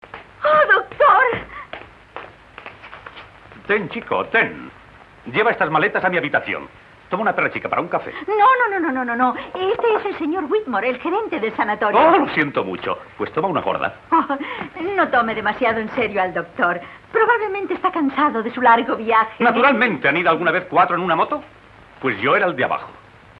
SONIDO (VERSIÓN DOBLADA)
El nivel de volumen es muy alto y muy homogéneo a lo largo de todo el metraje. Sin embargo, el nivel de ruido de fondo es muy superior al del sonido inglés. Además, los fragmentos musicales se resienten claramente, notándose una cierta distorsión en algunos momentos, que no oímos en la versión original.
La calidad de los diálogos permite su fácil comprensión, incluso en los momentos más alocados.